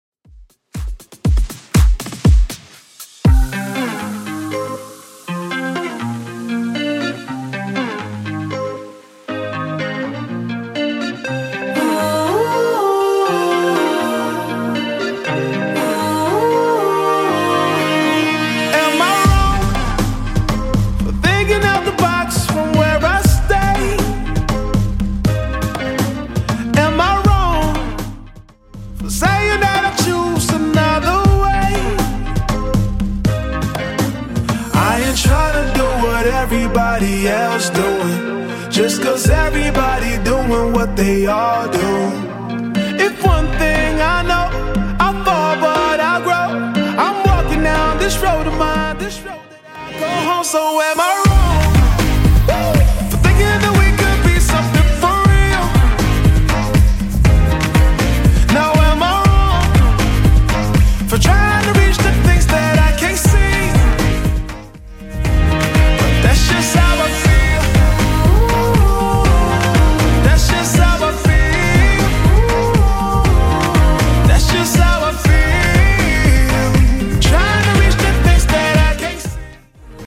Genre: 80's
BPM: 123